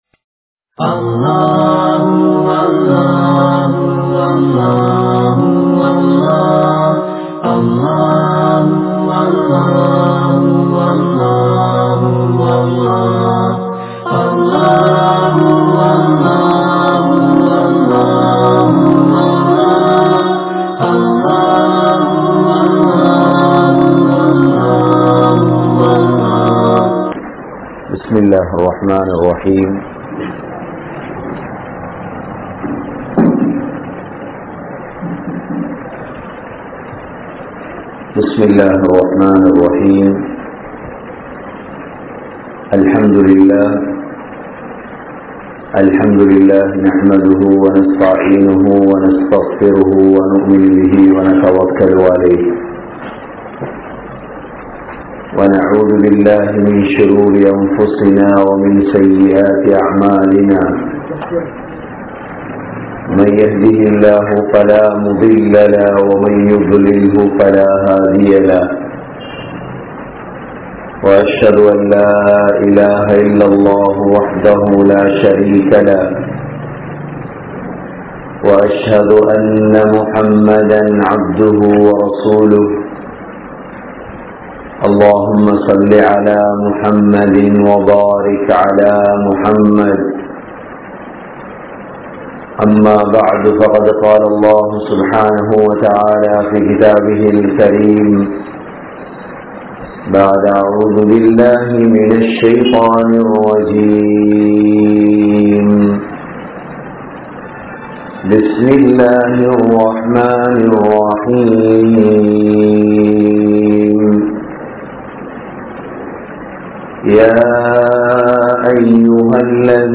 Kulanthaip Paakkiyam (குழந்தைப் பாக்கியம்) | Audio Bayans | All Ceylon Muslim Youth Community | Addalaichenai